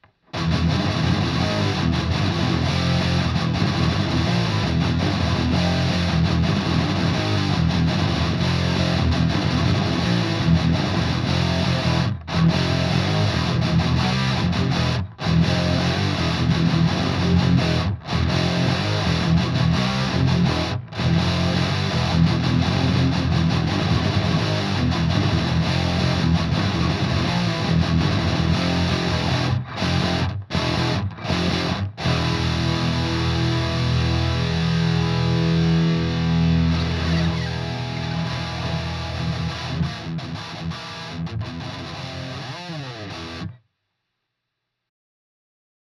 No Drums